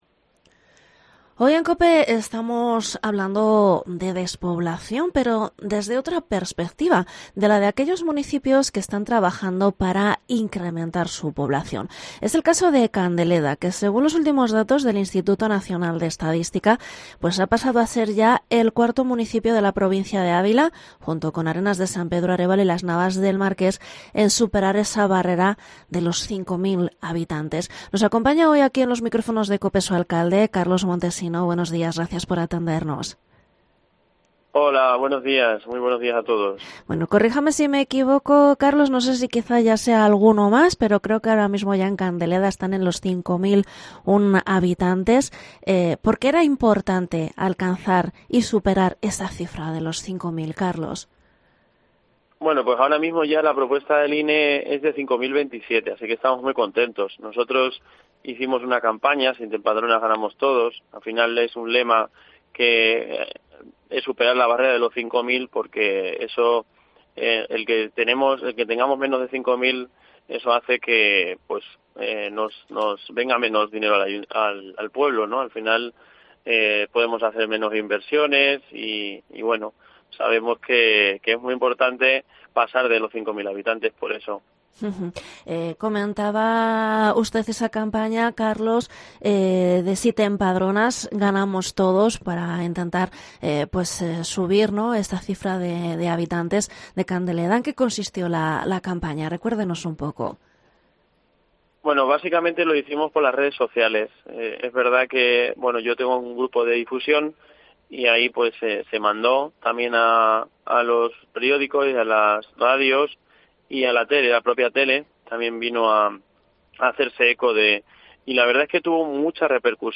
Entrevista al alcalde de Candeleda, Carlos Montesino